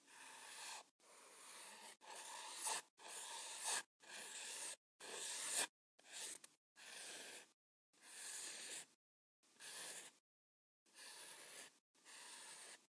描述：rayones sobre una hoja
Tag: 线 铅笔